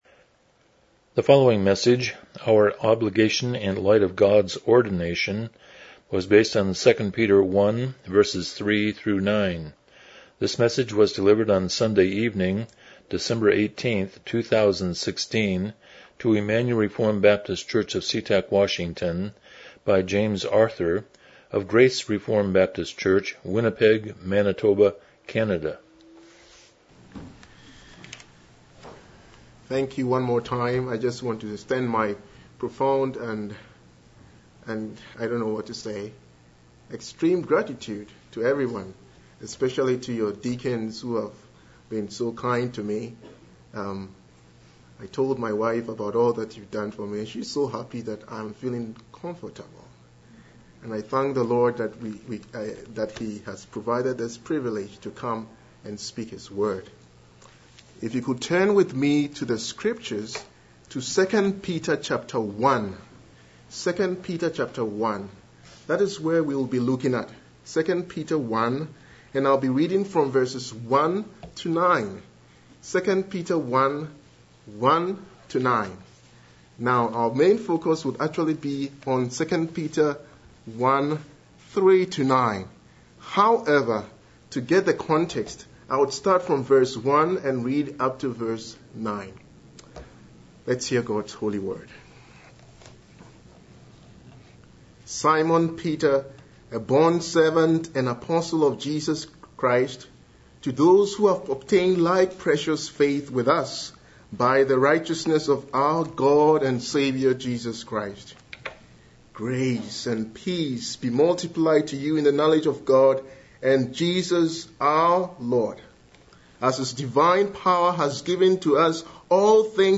2 Peter 1:3-9 Service Type: Evening Worship « God’s Gift of Christ Worldview